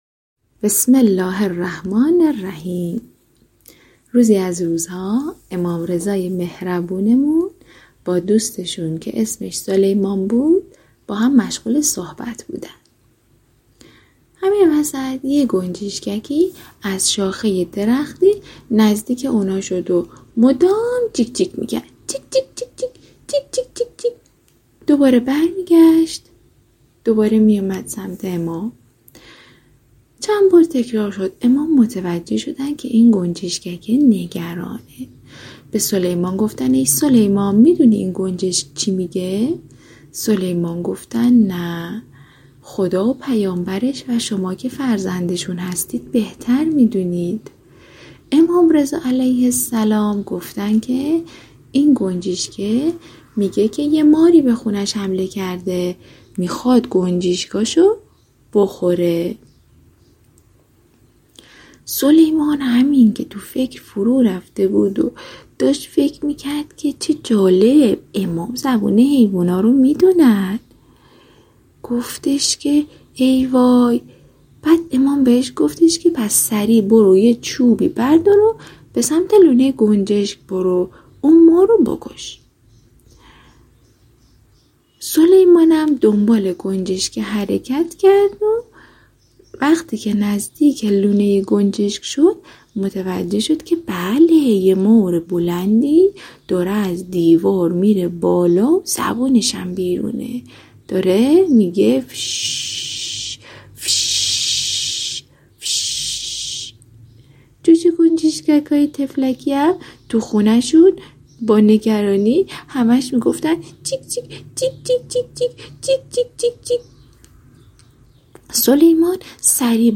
نمایش نامه داستان گنجشک و امام رضا علیه‌السلام